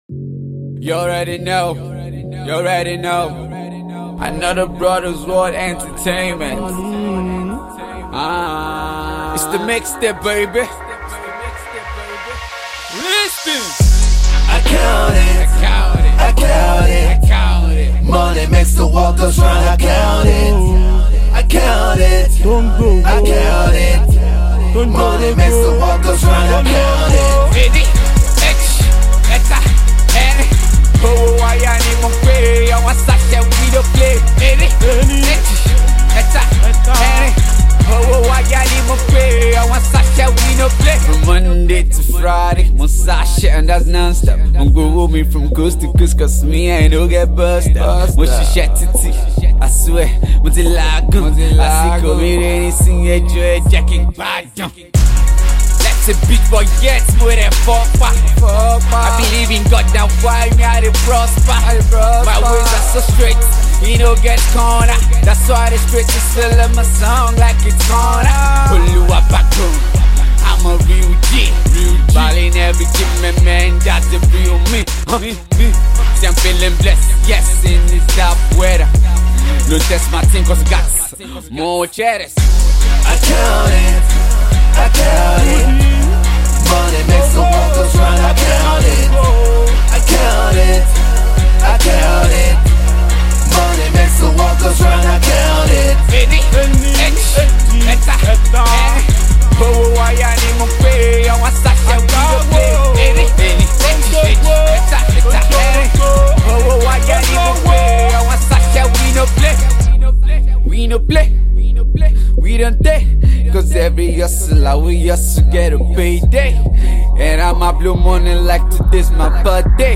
Street Rap